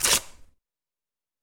paper.wav